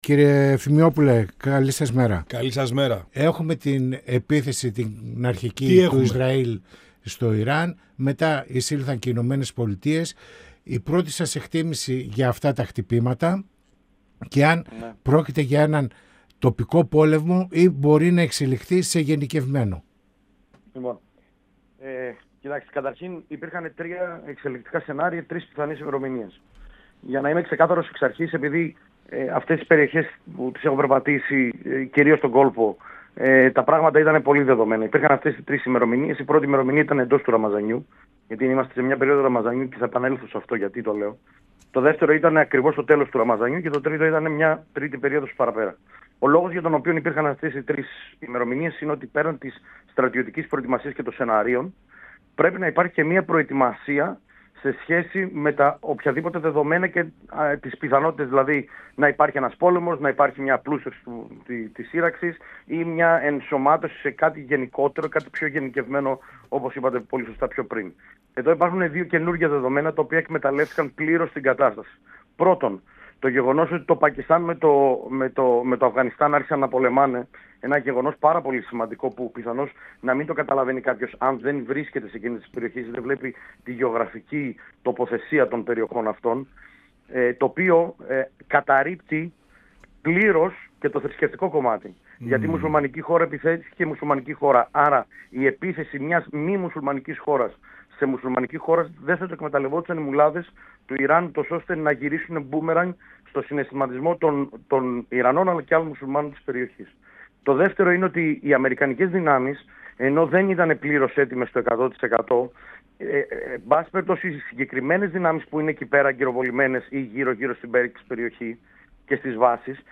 Πανοραμα Επικαιροτητας Συνεντεύξεις